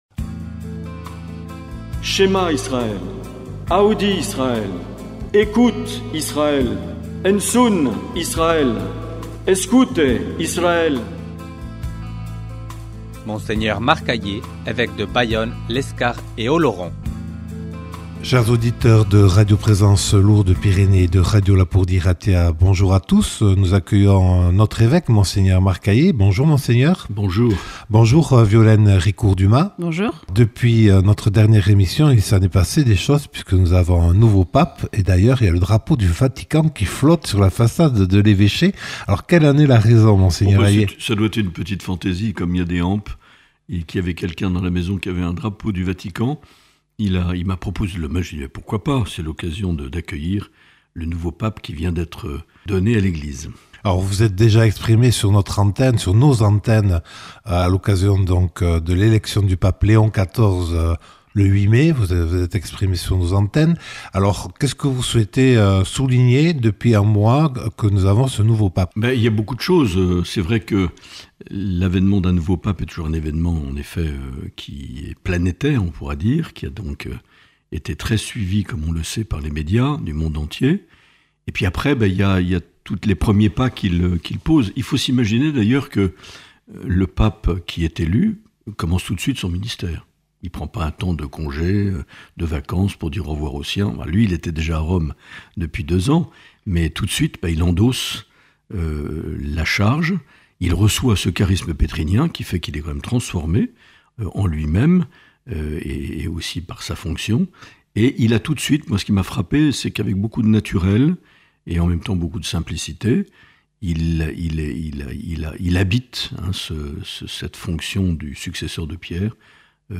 L’entretien avec Mgr Marc Aillet - Juin 2025